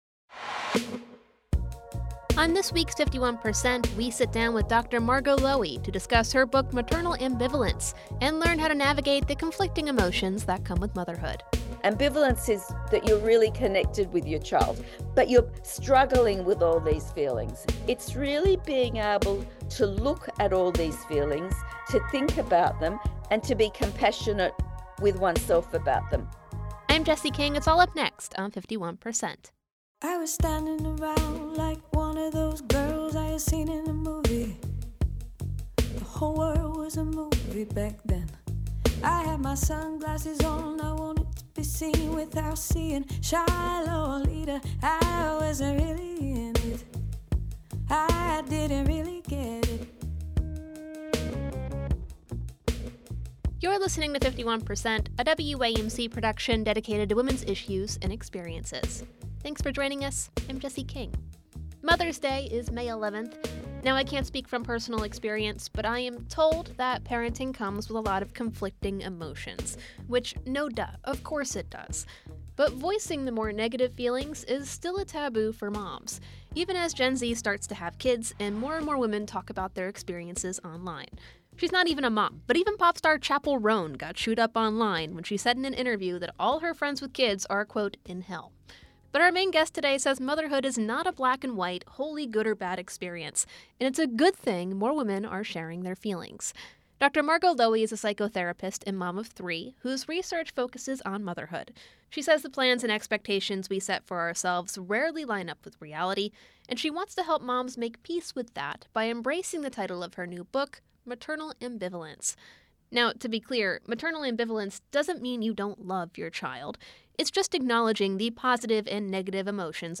Bitter Truths of Motherhood 51% is a national production of WAMC Northeast Public Radio in Albany, New York.